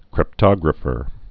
(krĭp-tŏgrə-fər)